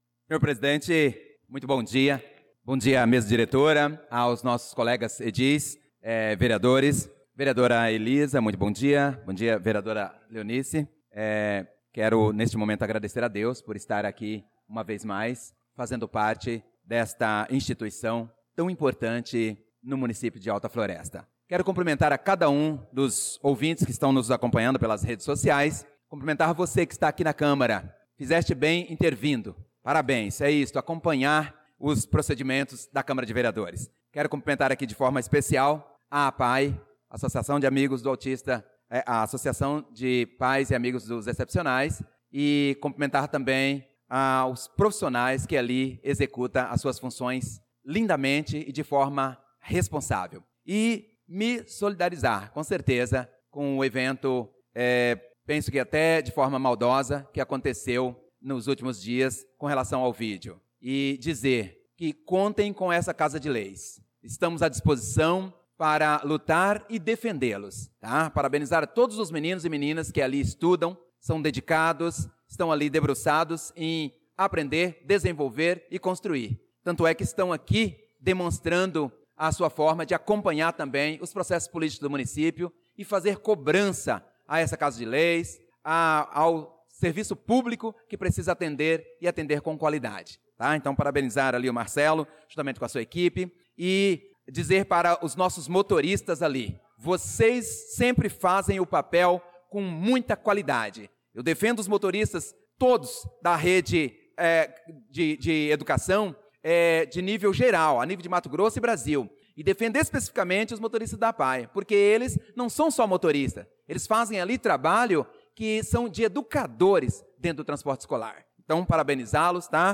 Pronunciamento do vereador Prof. Nilson na Sessão Ordinária do dia 06/03/2025